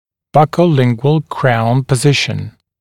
[ˌbʌkəu’lɪŋgwəl kraun pə’zɪʃn][ˌбакоу’лингуэл краун пэ’зишн]щечно-язычное положение коронки